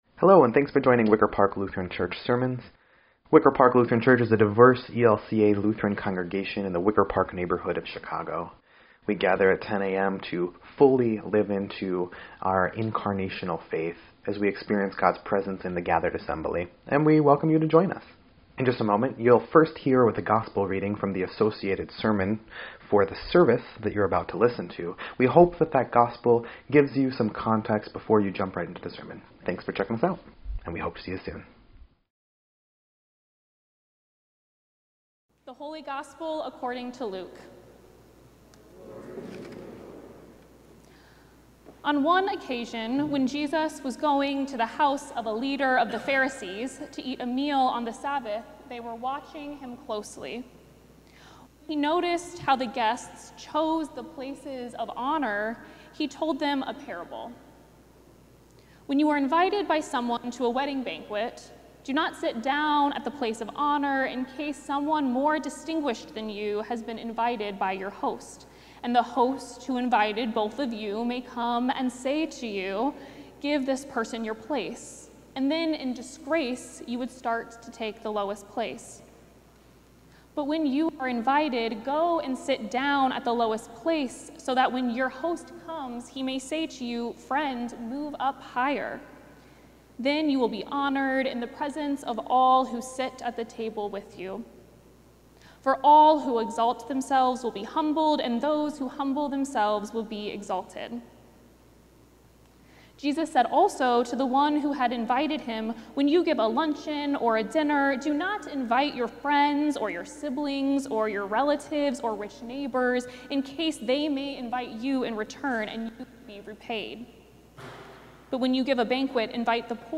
8.31.25-Sermon_EDIT.mp3